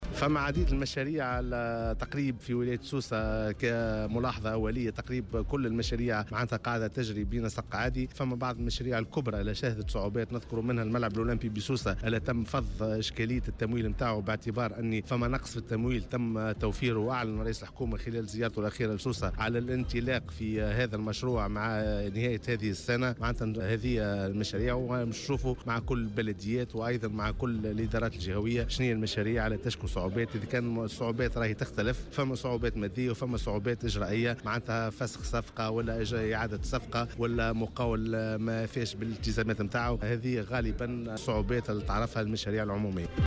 قال شكري بن حسن الوزير لدى رئيس الحكومة المكلف بالاقتصاد التضامني و الاجتماعي، في تصريح اليوم لـ"الجوهرة أف أم" على هامش جلسة عمل حول المشاريع العمومية بولاية سوسة، إن أغلب المشاريع بالجهة تسير بنسق عادي.